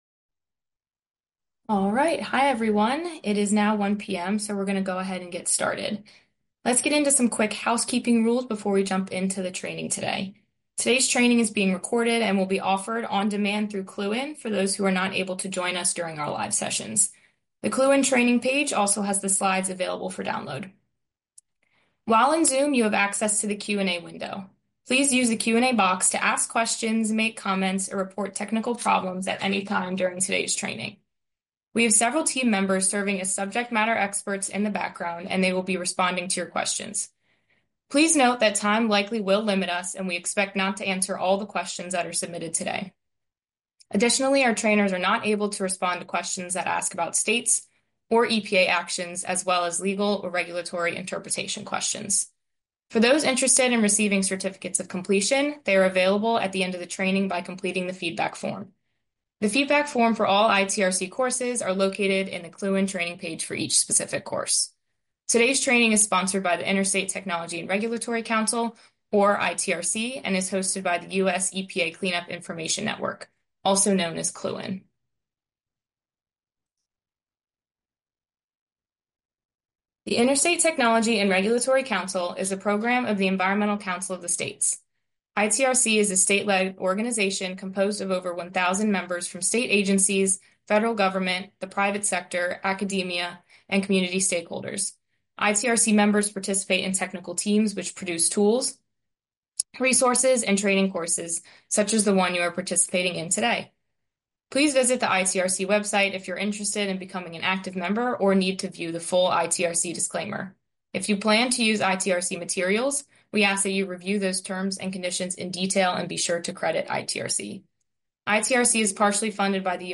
This training class builds on the earlier information for fate and transport and site characterization presented in the PFAS 101 CLU-IN training. It provides more in-depth information for fate and transport, site characterization, source identification and some introductory information on environmental forensics.